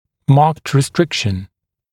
[mɑːkt rɪ’strɪkʃn][ма:кт ри’стрикшн]выраженное ограничение